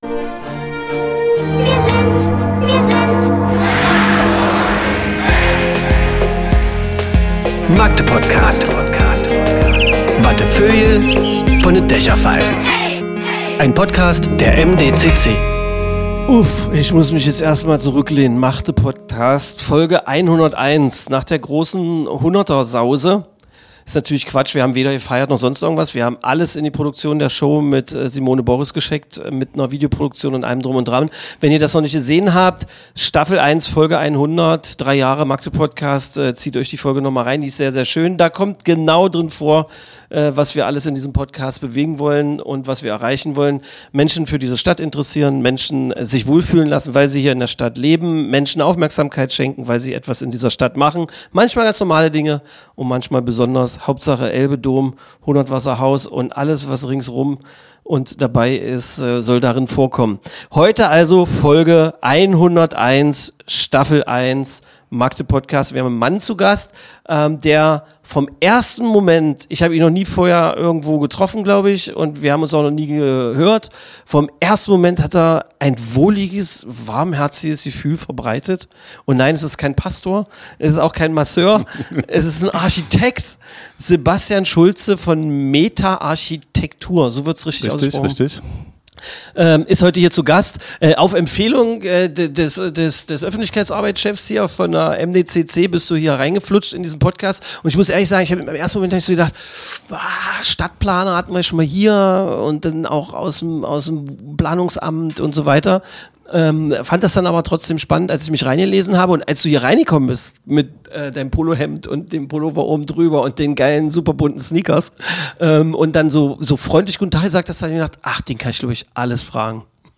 Höre, was bewegt - Talk im Türmchen und über den Dächern der Stadt